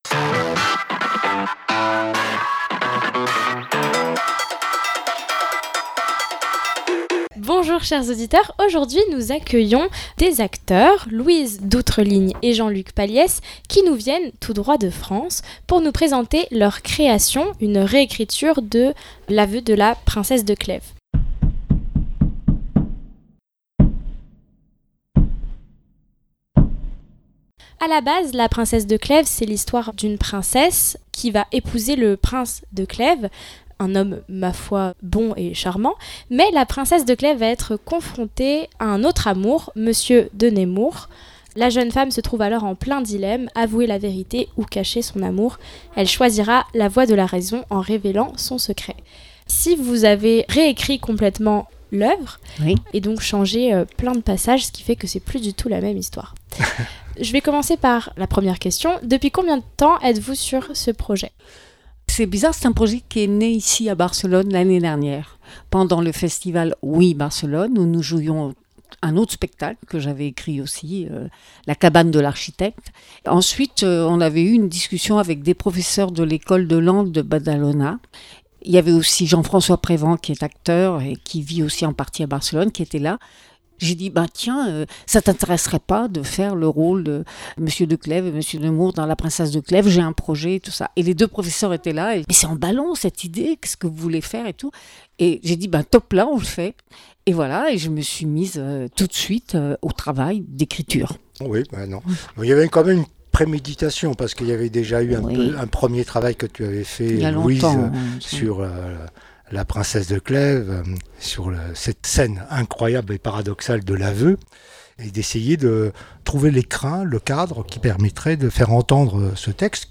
Conférence - Débat - Littérature - Culture = Des invités au micro de la Web Radio.